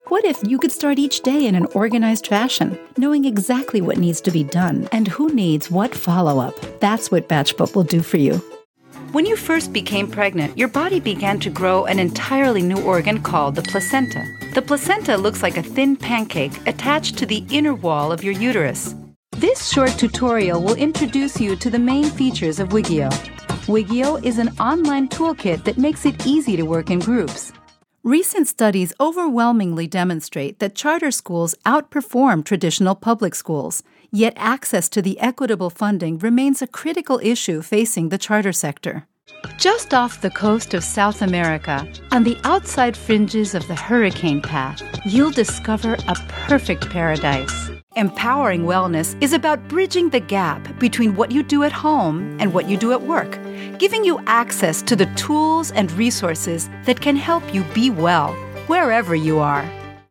Erzählung
Meine Kunden beschreiben meine Stimme am besten als freundlich, spritzig, ausdrucksstark, angenehm, warm und enthusiastisch